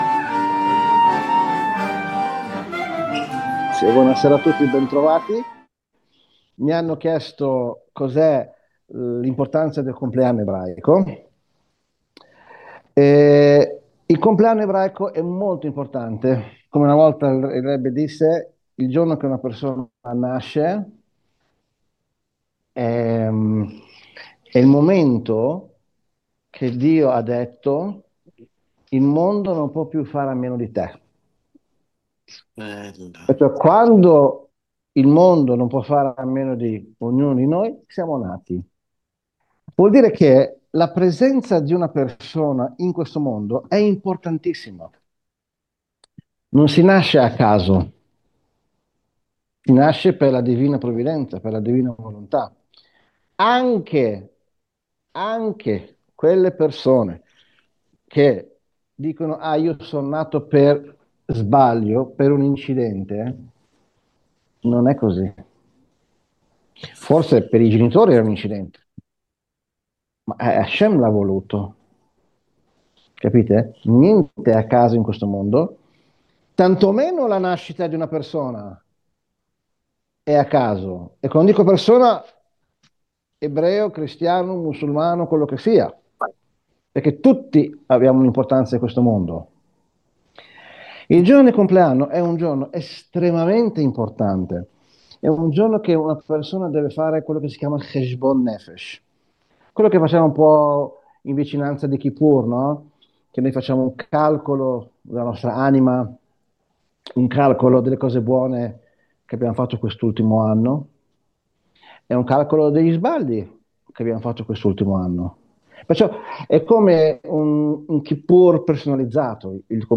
Lezione del 21 marzo 2026